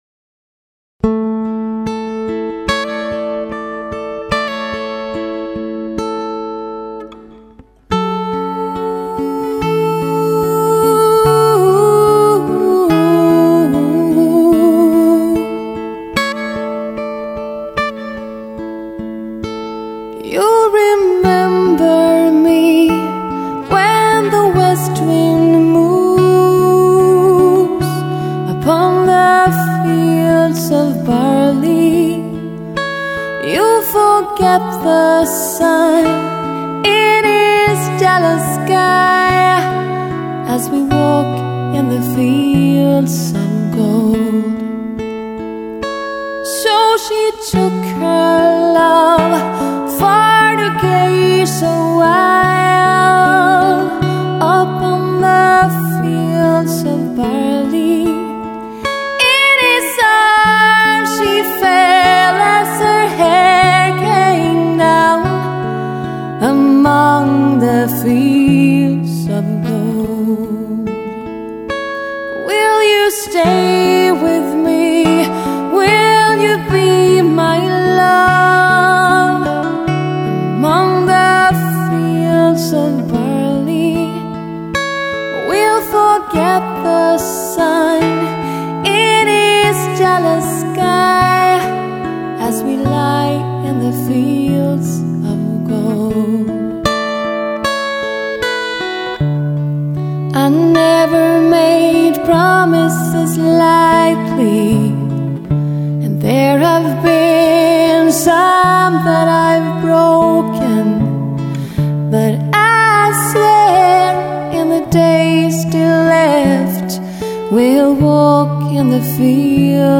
• Coverband
• Duo/trio